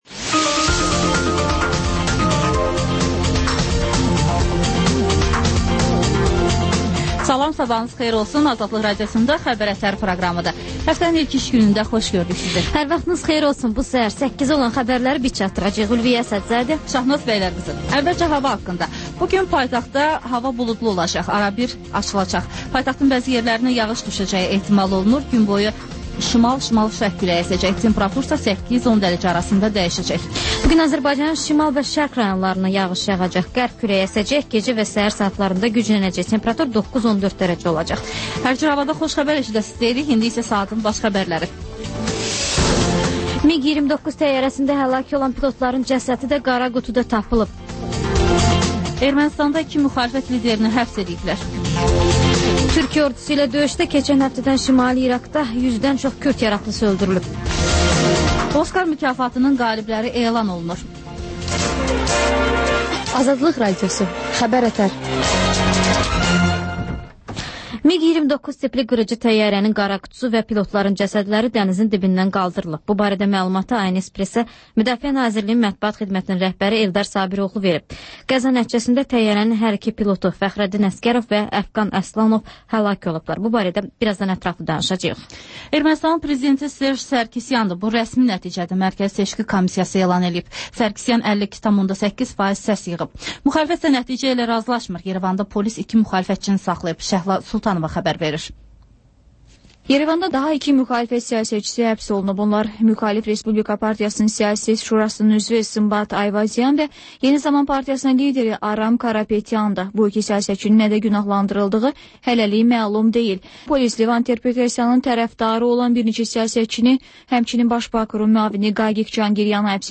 Xəbər-ətər: xəbərlər, müsahibələr və İZ: mədəniyyət proqramı